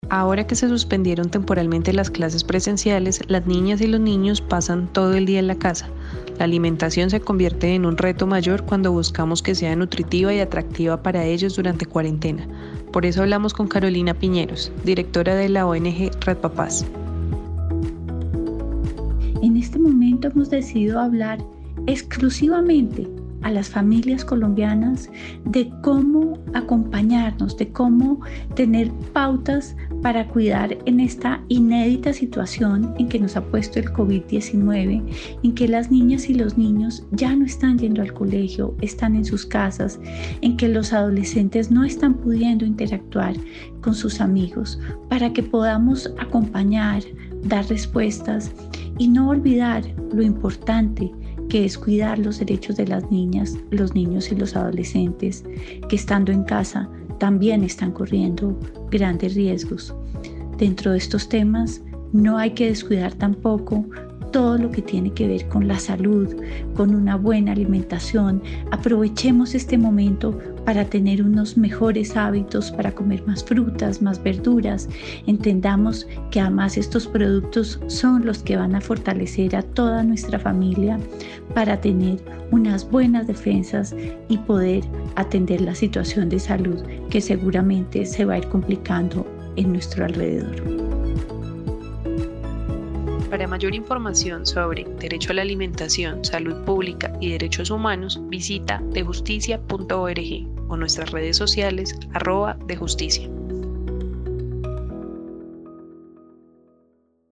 Escucha una serie de audios sobre el derecho a la alimentación, bajo la voz de diferentes expertas.